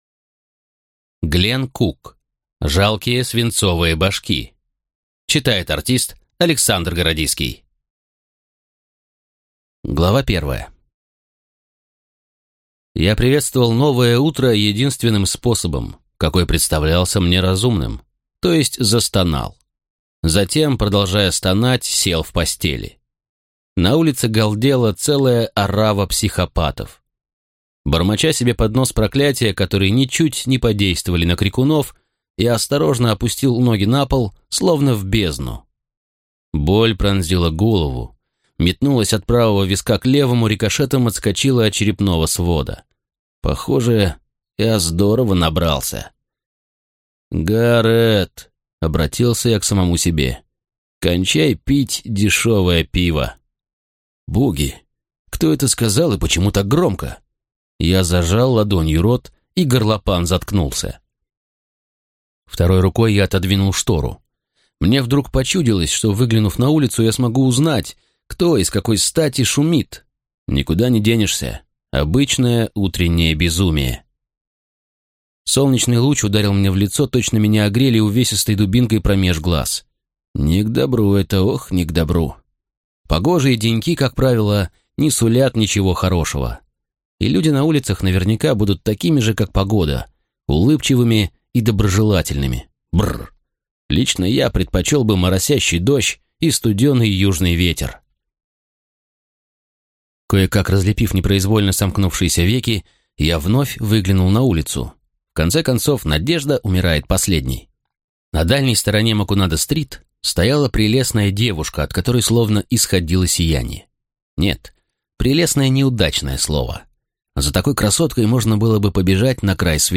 Аудиокнига Жалкие свинцовые божки | Библиотека аудиокниг